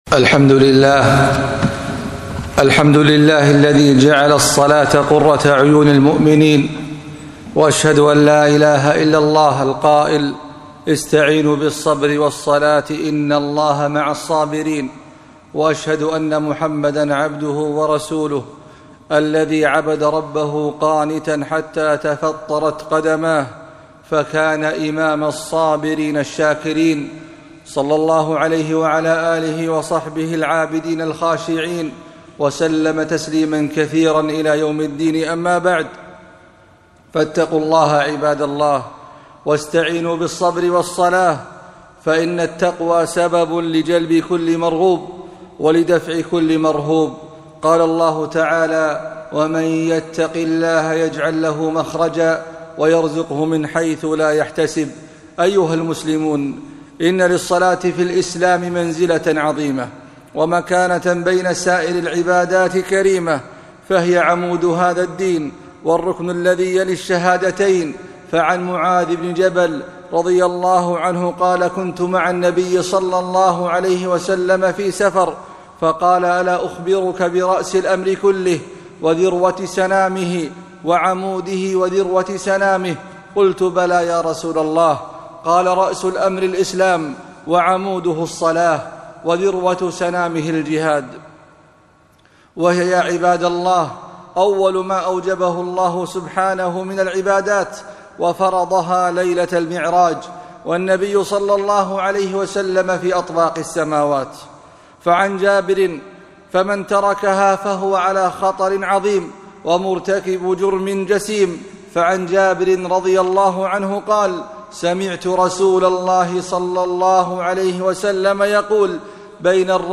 خطبة - الصلاة عمود الدين